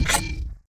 Select Robot 3.wav